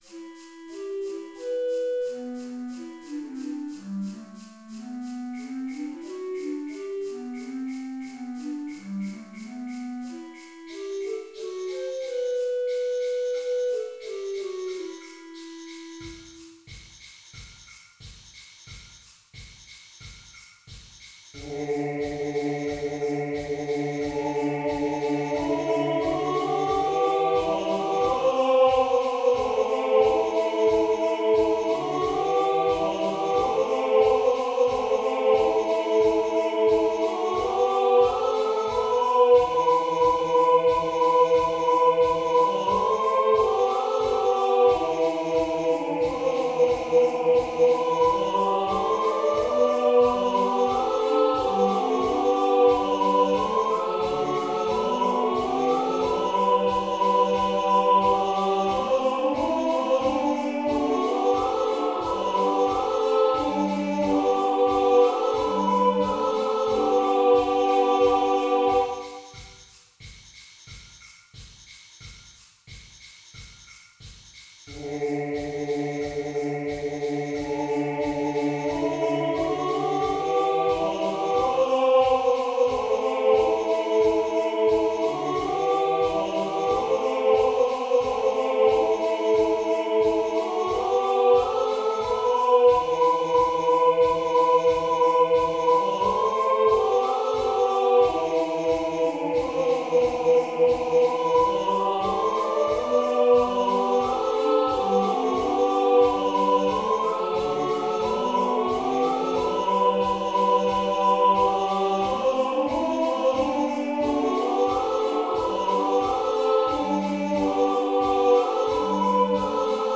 Contralto
Tenor
flute